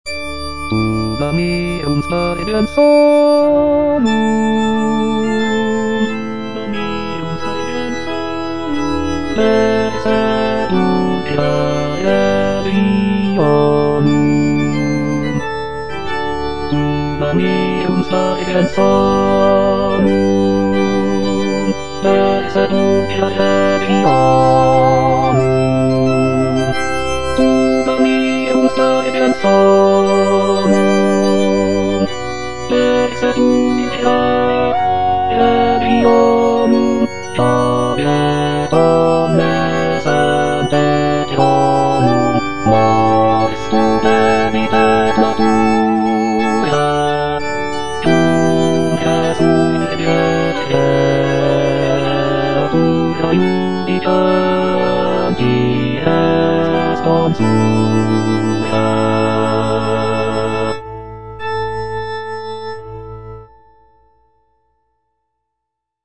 Bass (Emphasised voice and other voices) Ads stop